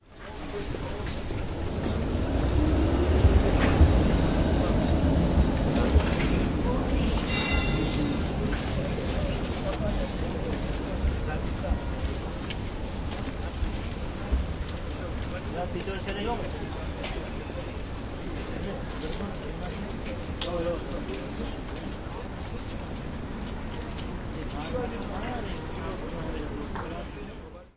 无轨电车
描述：记录在基辅的无轨电车
标签： 基辅 户外录音 无轨电车
声道立体声